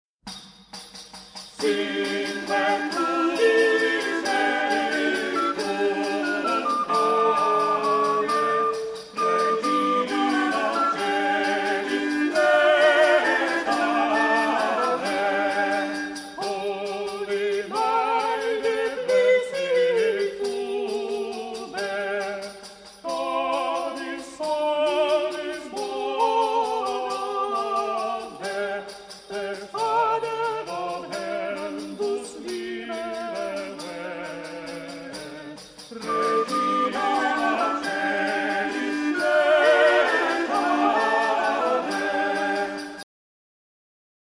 Sing We To This Merry Company (Middle English Lyric; Medieval Carol)
Audio Excerpt by the Purcell Consort from the album